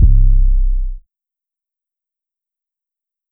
808 (R.I.P. SCREW).wav